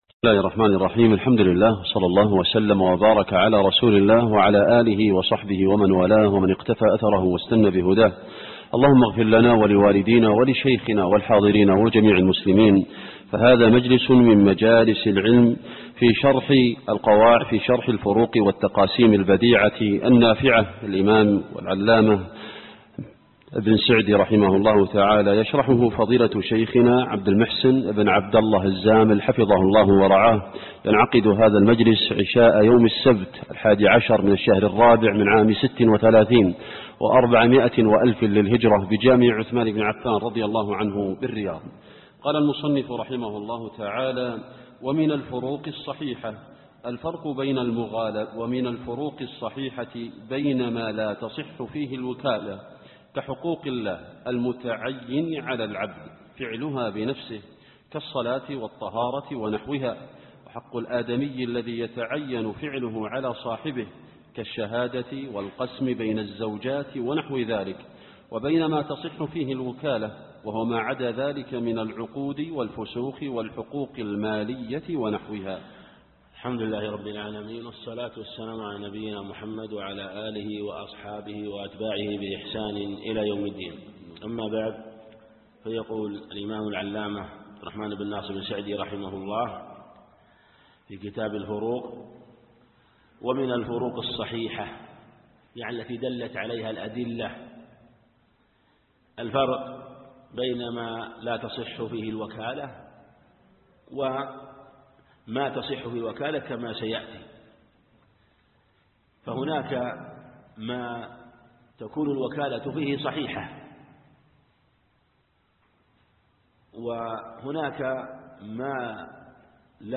شرح الفروق والتقاسيم البديعة النافعة لابن سعدي الدرس